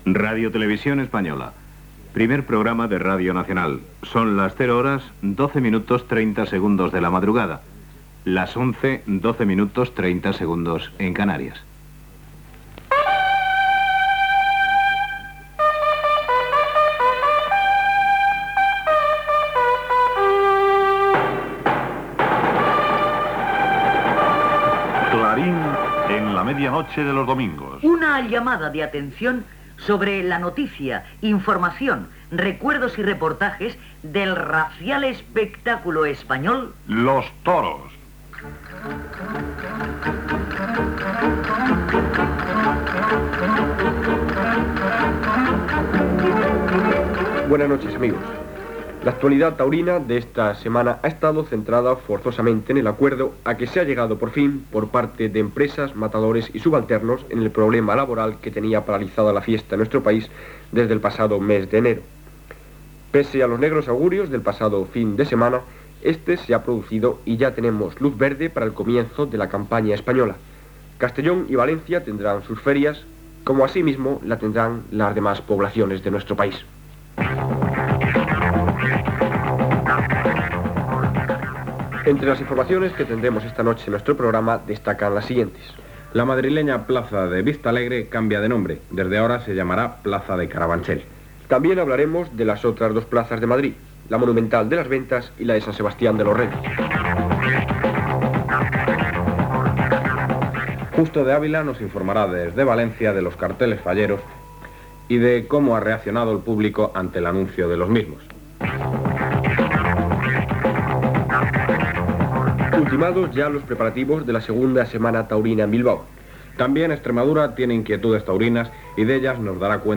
Identificació de l'emissora, com RTVE Primer Programa de Radio Nacional, hora, careta del programa, sumari informatiu, comentari d'actualitat taurina, cartells de les places de Castelló i València, el torero Paco Camino, sous dels professionals taurins Gènere radiofònic Informatiu